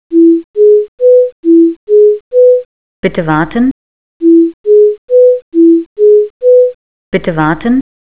Music-On-Hold: